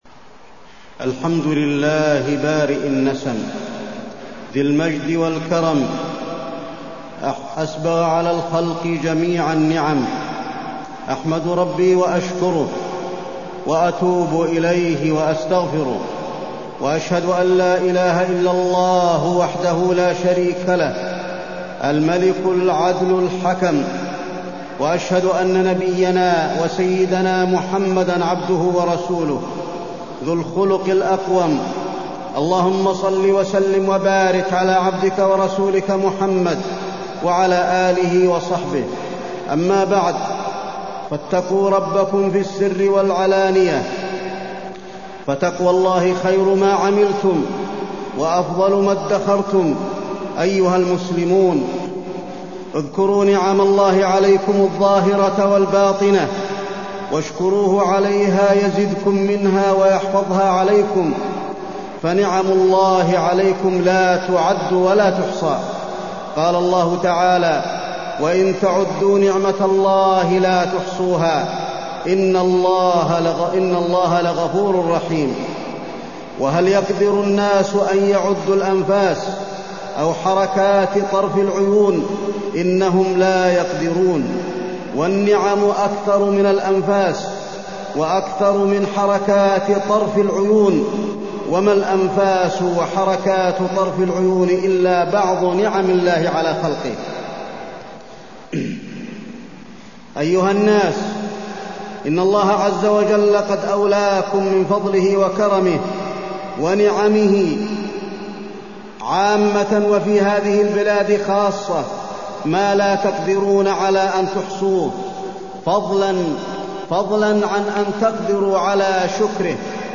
تاريخ النشر ٢٩ رجب ١٤٢٤ هـ المكان: المسجد النبوي الشيخ: فضيلة الشيخ د. علي بن عبدالرحمن الحذيفي فضيلة الشيخ د. علي بن عبدالرحمن الحذيفي نعم الله على العباد